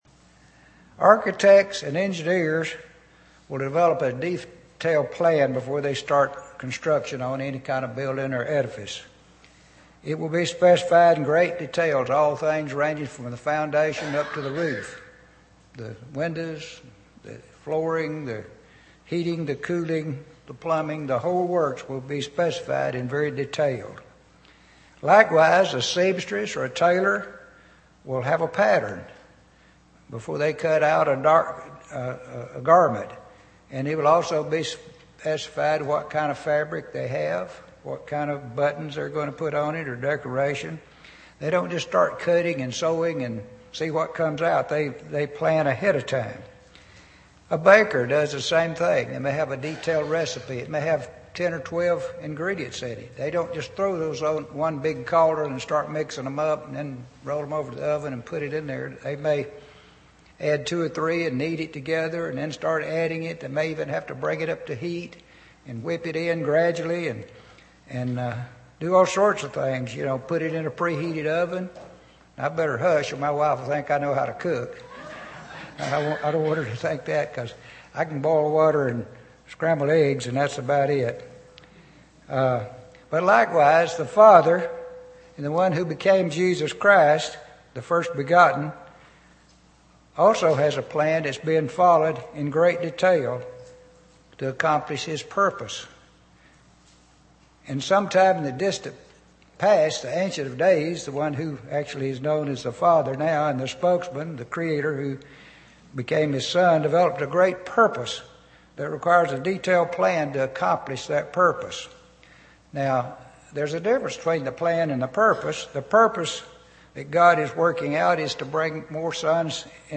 Given in East Texas
UCG Sermon Studying the bible?